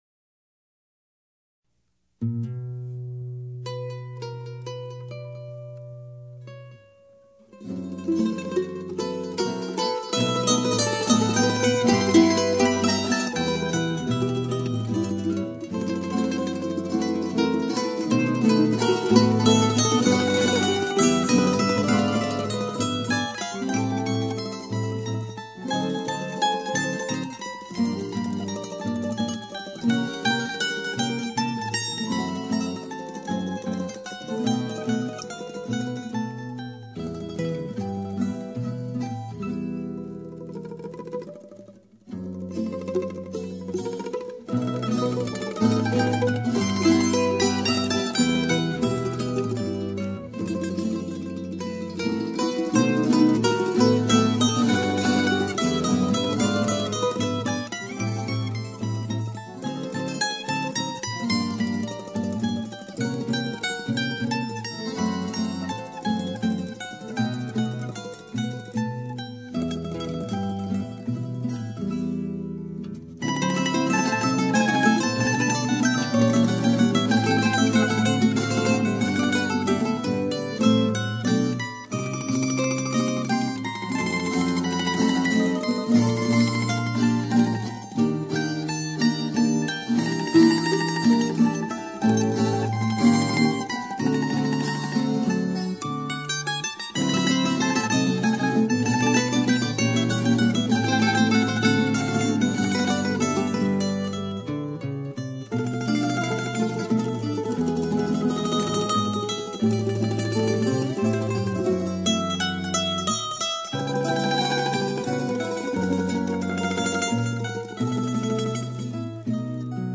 Here is an mp3 of a tune featuring the Colombian tiple.